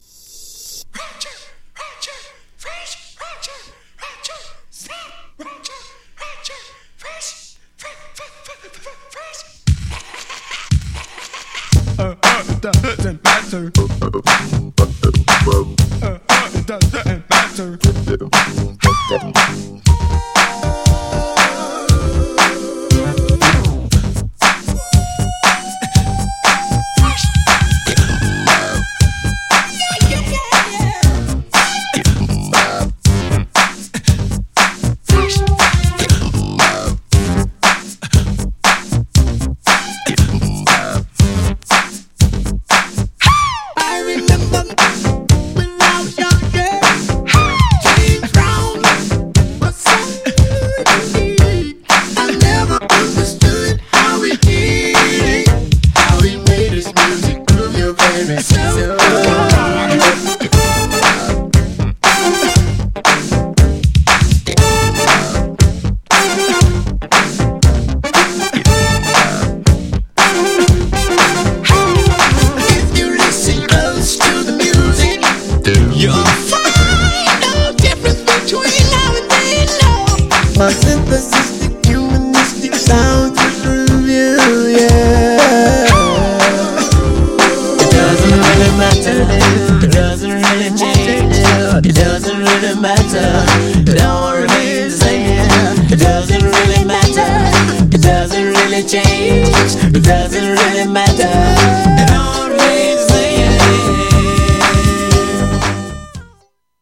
全編ボコーダで歌ったメロウナンバーのA面に、
エレクトリックFUNKなB面、両面人気のUK12"!!
GENRE Dance Classic
BPM 86〜90BPM